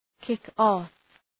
Προφορά
{‘kıkɒf}